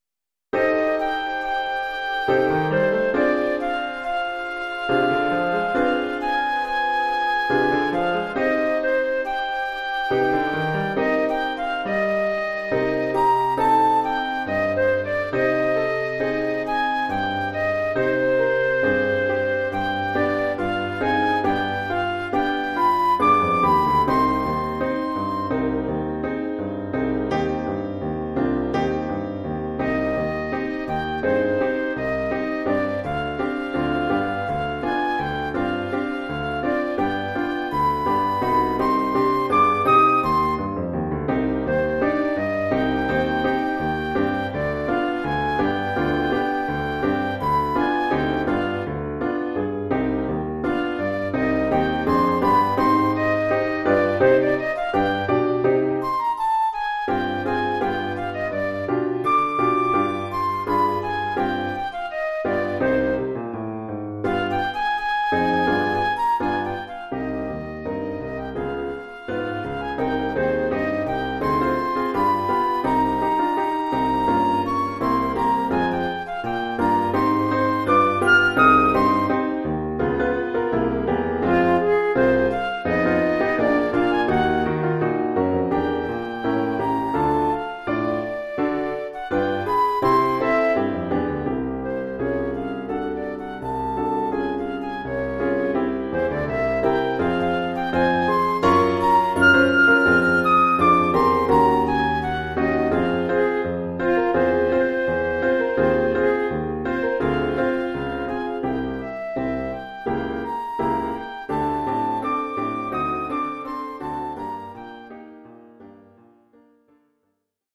1 titre, flûte et piano : conducteur et partie de flûte
Oeuvre pour flûte et piano.